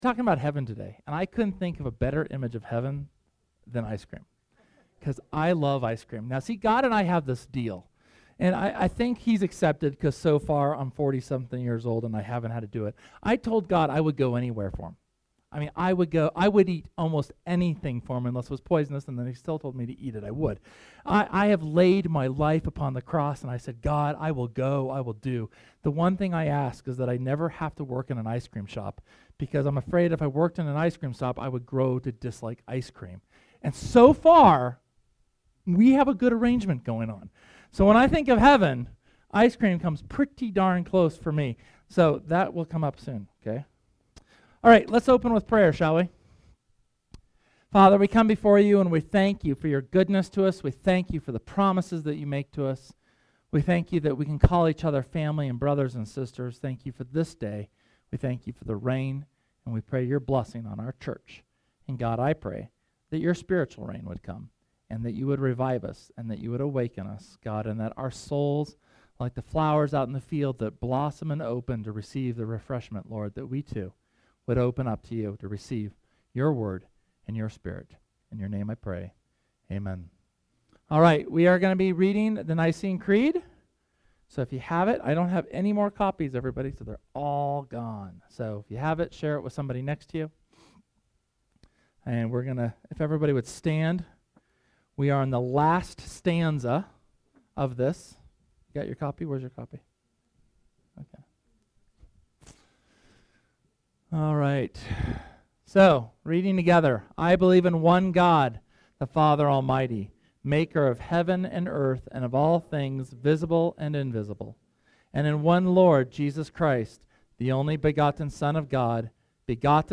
Here it is, the final sermon on the Christian Creeds. Our closing sermon on message and lessons of the Christian Creeds and their impact upon our daily lives and the hope we aspire.